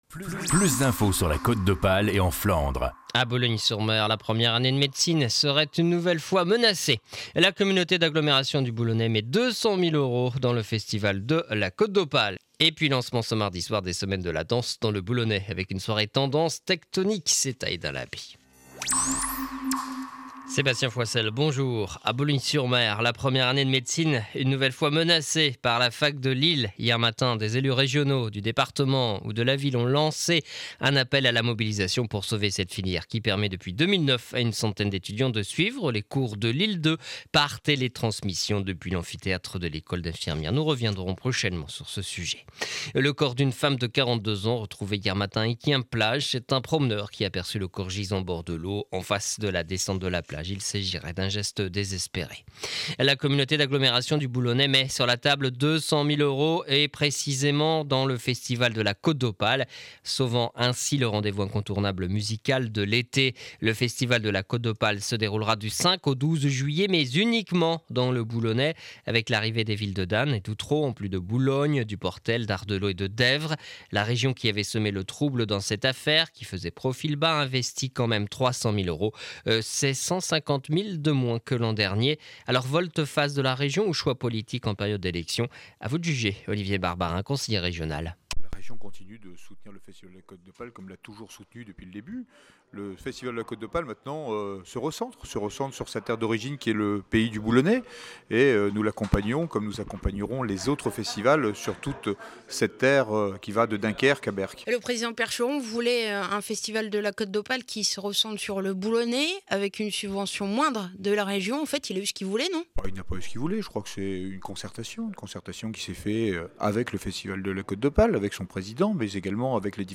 Journal du jeudi 20 mars 2012 7 heures 30 édition du Boulonnais.